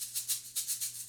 Shaker 07.wav